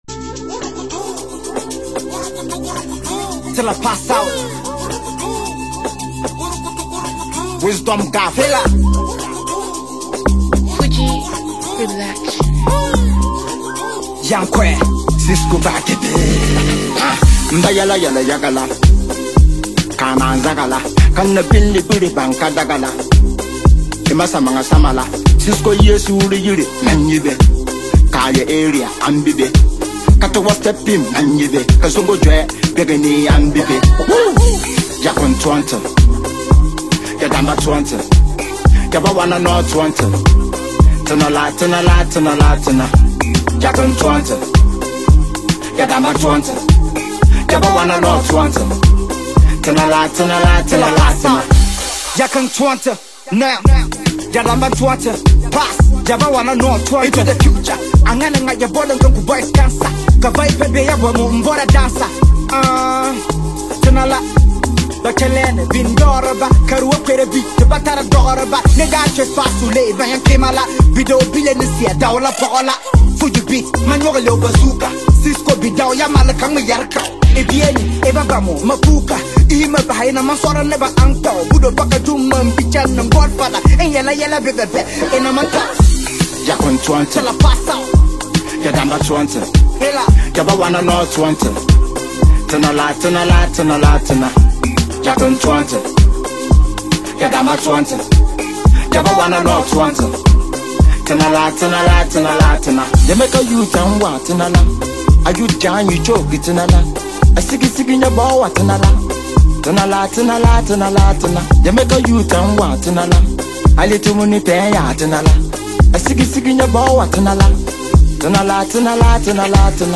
who recruited the sensational and top-notch rapper